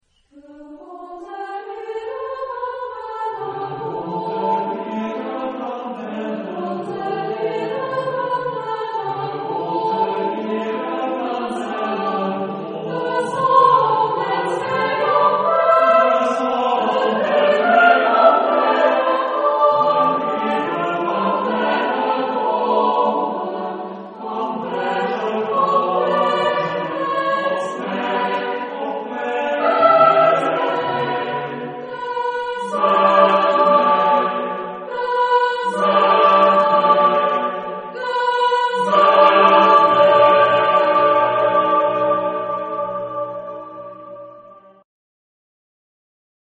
Genre-Stil-Form: weltlich ; zeitgenössisch
Charakter des Stückes: geheimnisvoll ; andante
Chorgattung: SSATB  (5 gemischter Chor Stimmen )
Tonart(en): verschiedene ; aleatorisch ; Sprechchor